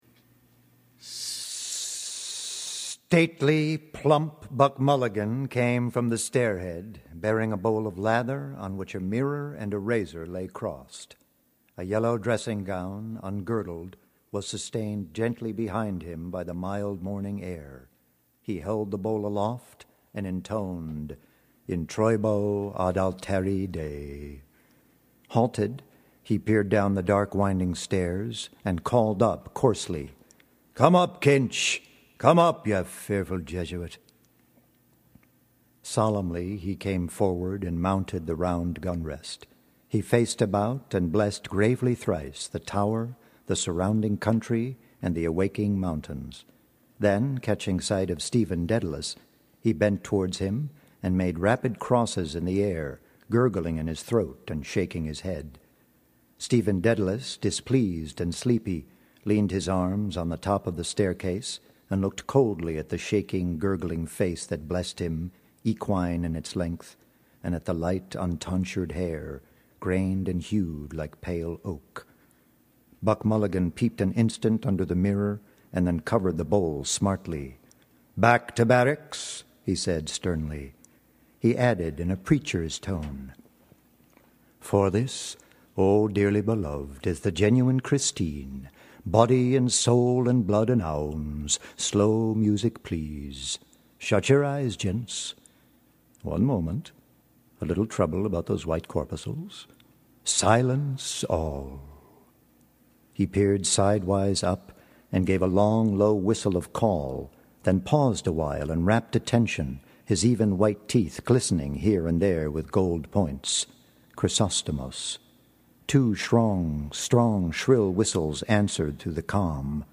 Excerpts from various broadcasts